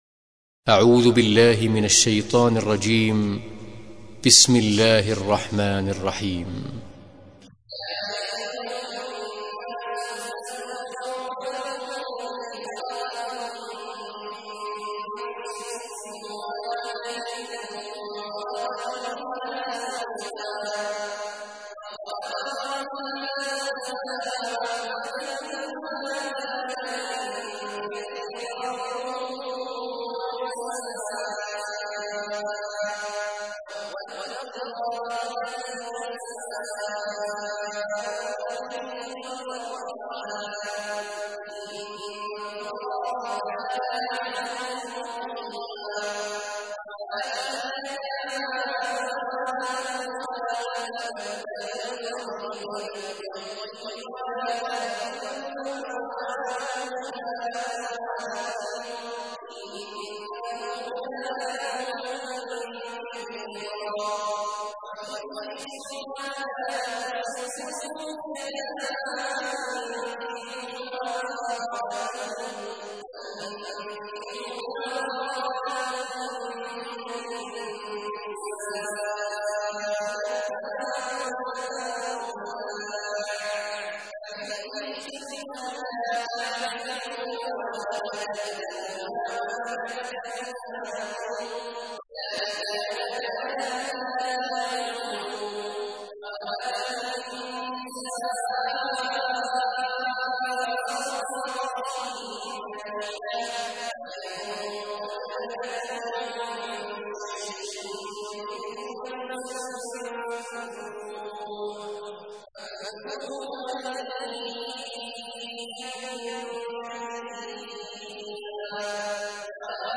تحميل : 4. سورة النساء / القارئ عبد الله عواد الجهني / القرآن الكريم / موقع يا حسين